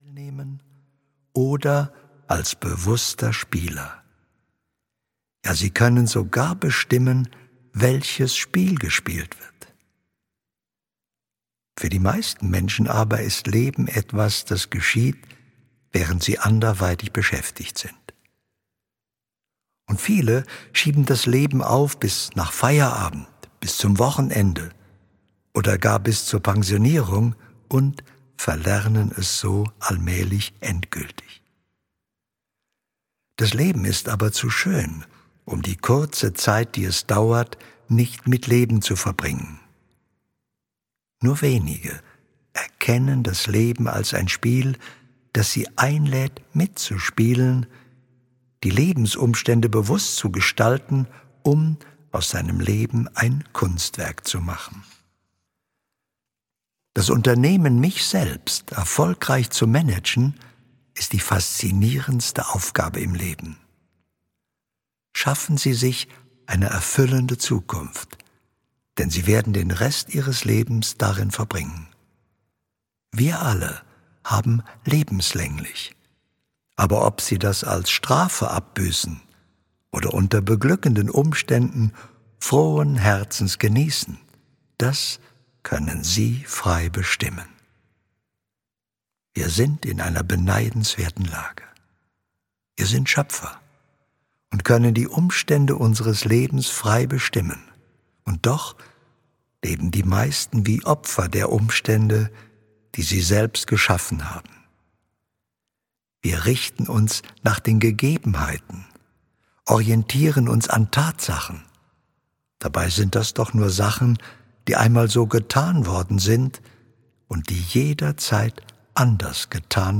Alltagsrezepte: Wie man sein Leben führt - Hörbuch